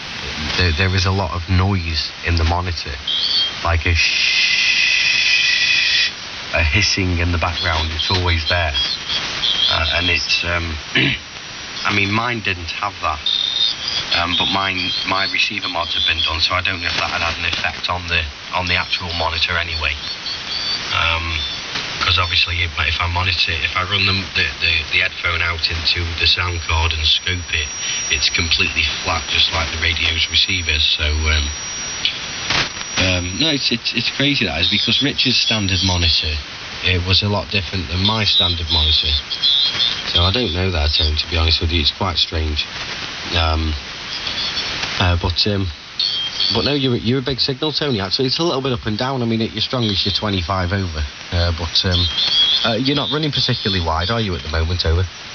Recorded using Kenwood TS-950SDX @ 0 - 6000Hz
To appreciate the full fidelity of the enhanced frequency response.